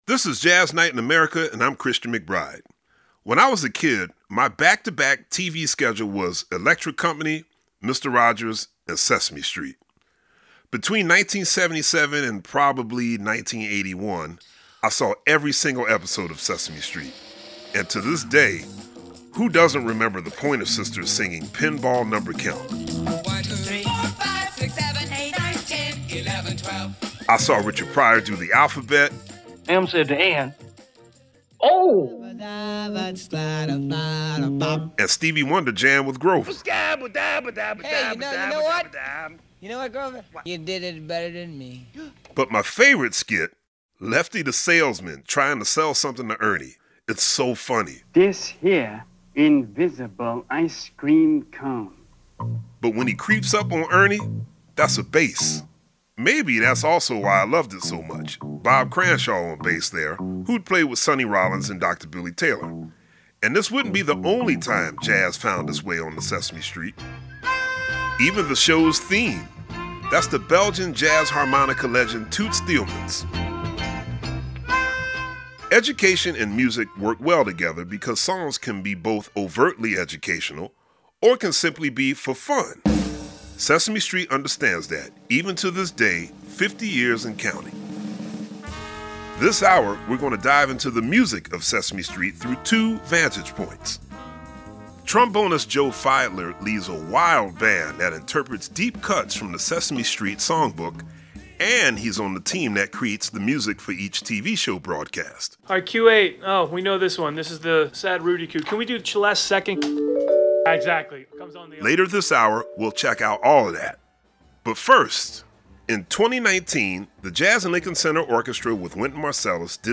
trombonist